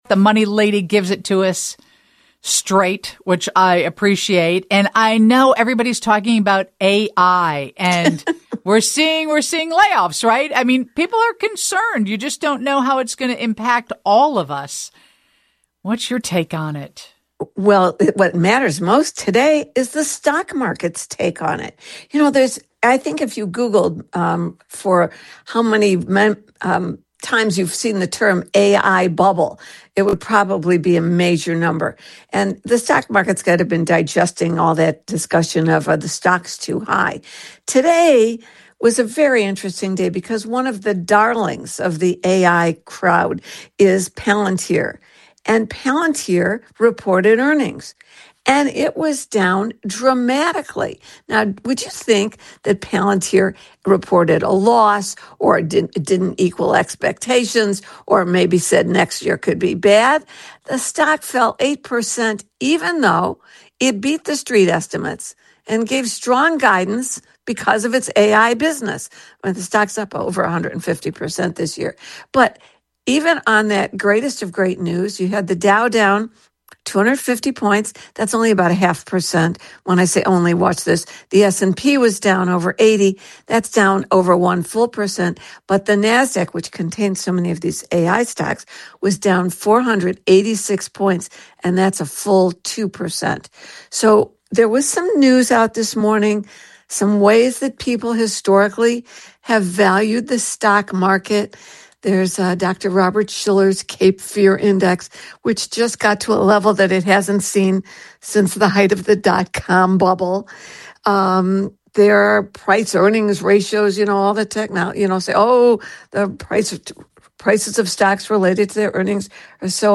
Then, as always, she answers questions from listeners.